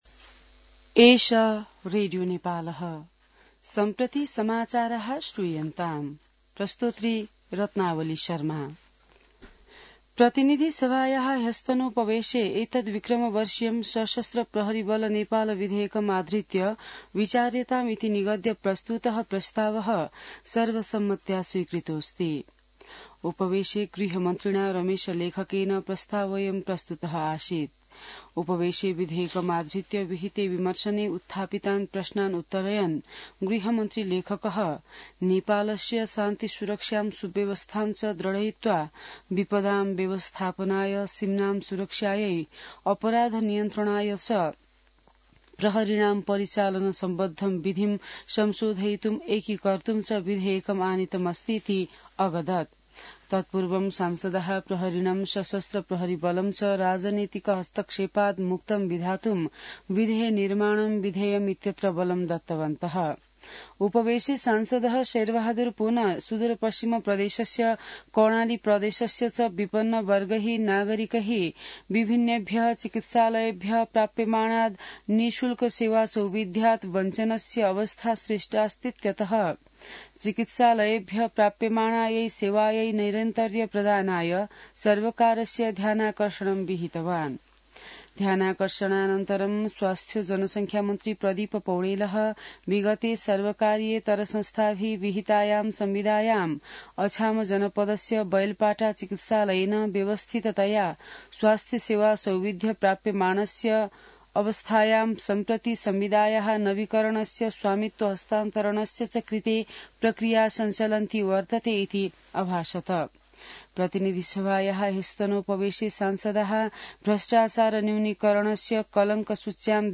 संस्कृत समाचार : १४ फागुन , २०८१